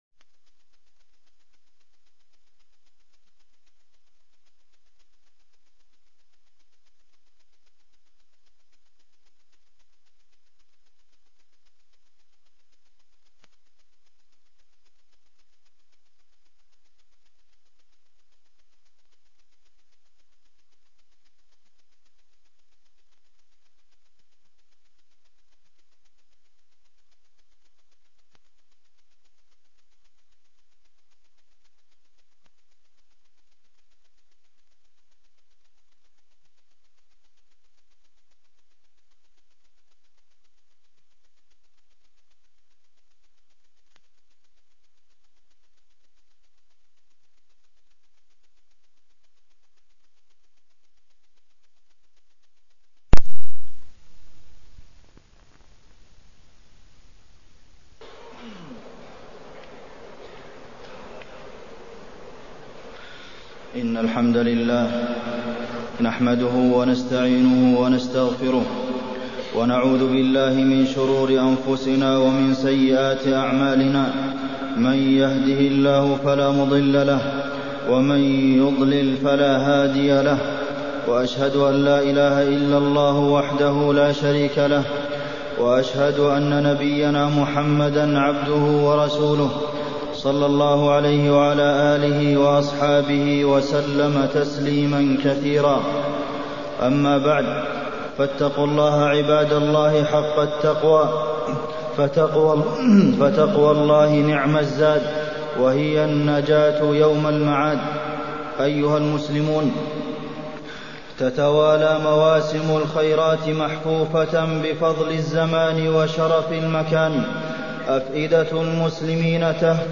تاريخ النشر ٨ ذو الحجة ١٤٢٤ هـ المكان: المسجد النبوي الشيخ: فضيلة الشيخ د. عبدالمحسن بن محمد القاسم فضيلة الشيخ د. عبدالمحسن بن محمد القاسم الحـج